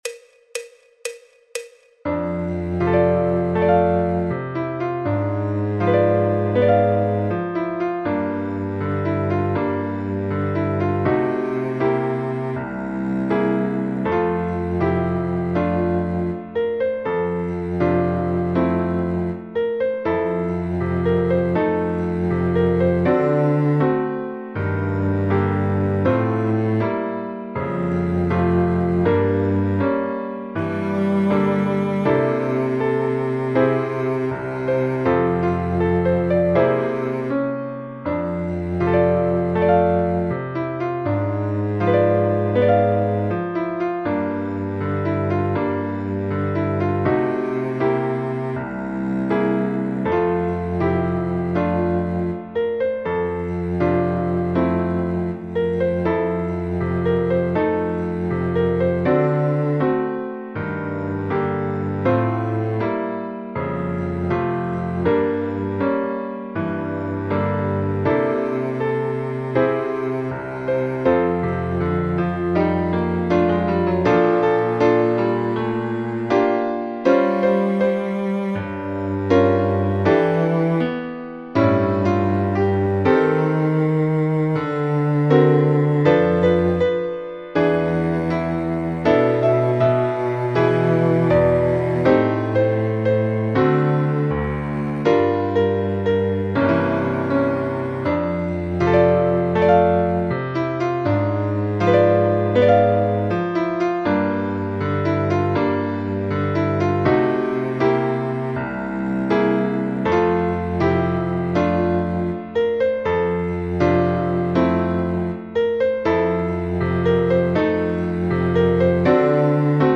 El MIDI tiene la base instrumental de acompañamiento.
Fa Mayor
Jazz, Popular/Tradicional